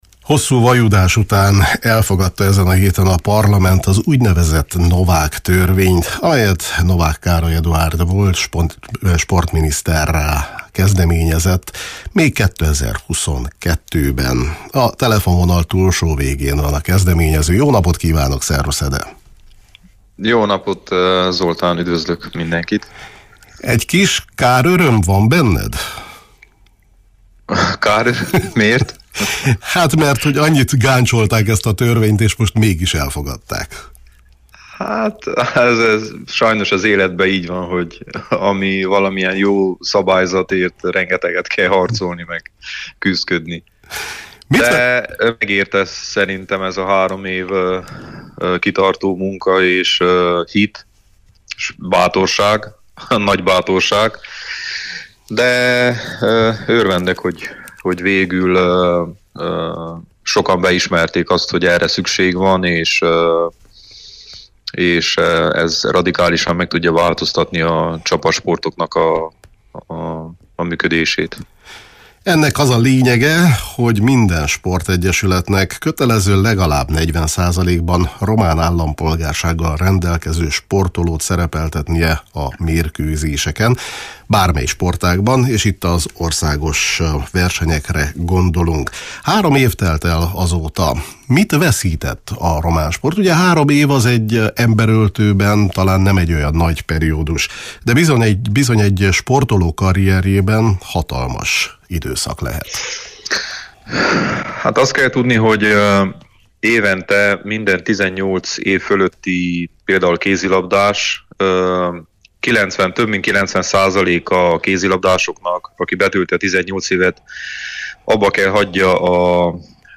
A kezdeményező Novák Károly Eduárd volt a Kispad című sportműsorunkban